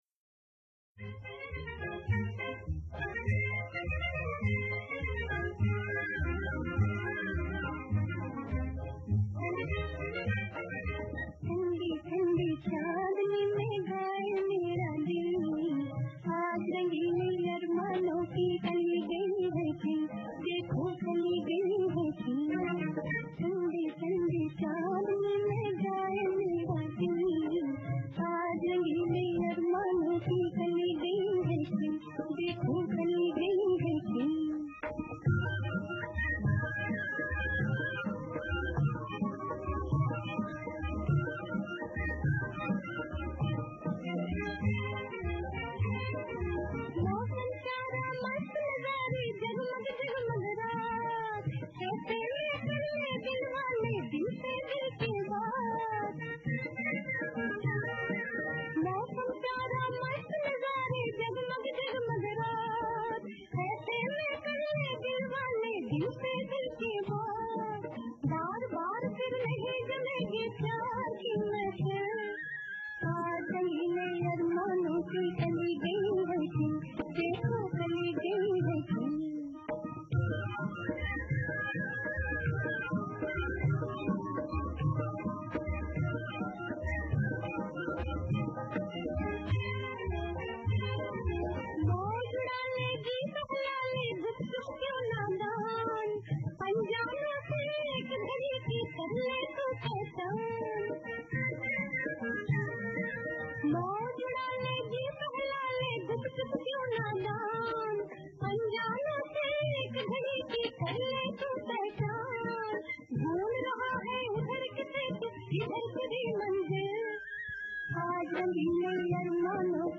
Bollywood Songs